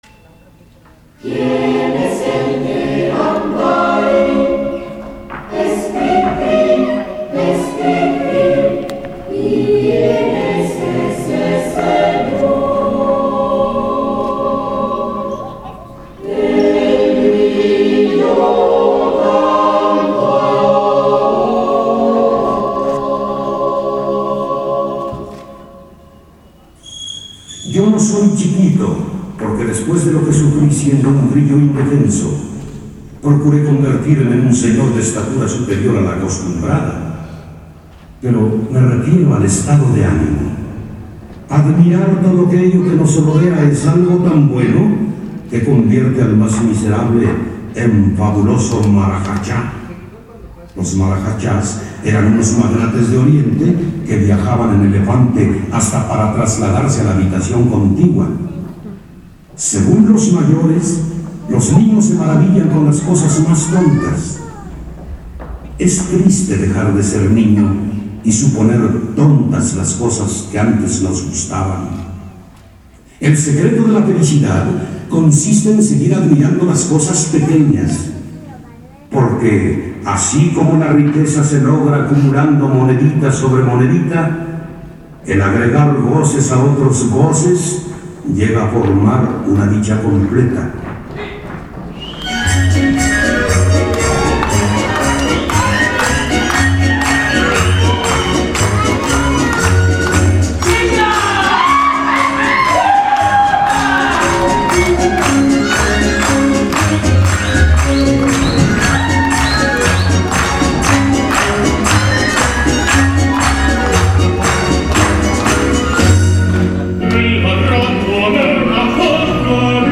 fragmento_ballet_cri_cri.mp3